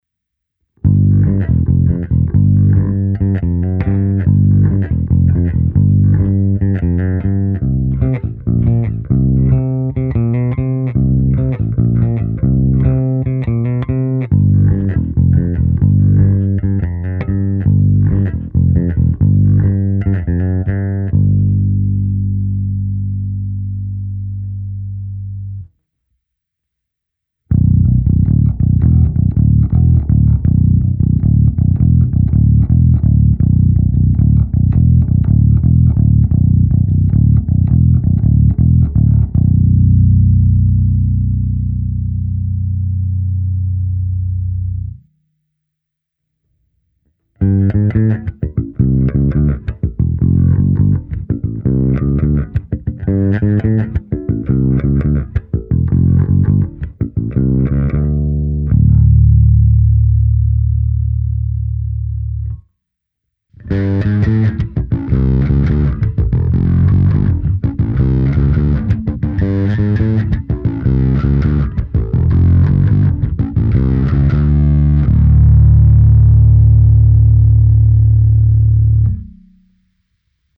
Nahrávka se simulací aparátu, kde byla použita i hra na struně H a také zkreslení. Slap díky použitým strunám nevyzní dobře, navíc na mé chabé schopnosti slapování je 6 strun zkrátka moc. U této ukázky jsem každopádně použil nastavení, které se mi nejvíce líbí, tedy s lehce upřednostněným kobylkovým snímačem.